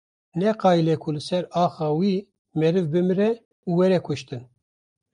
Pronounced as (IPA)
/kʊʃˈtɪn/